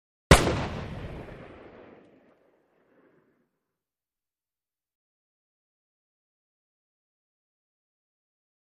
Musket Fire
Black Powder Musket 3, Single Shot. More Powerful And More Ring Off Than FX 18 Or 19.